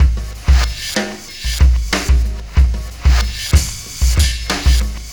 Black Hole Beat 07.wav